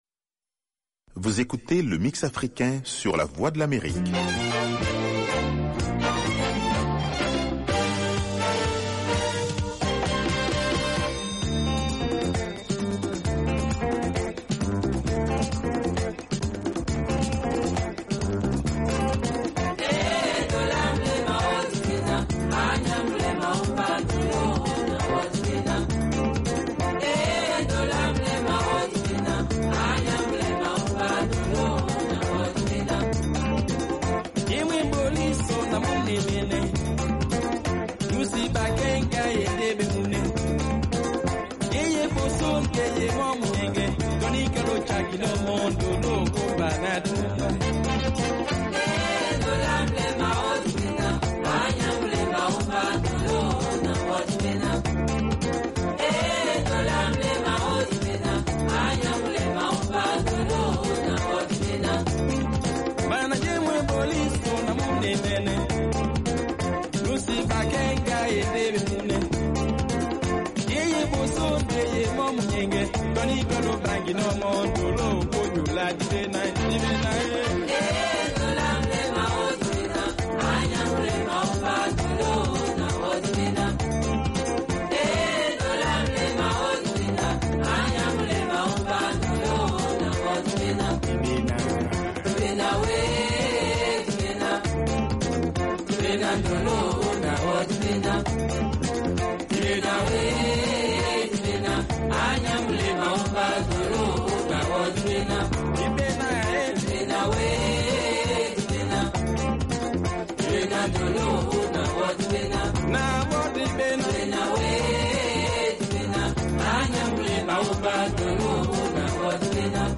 une sélection spéciale de musique malienne et internationale.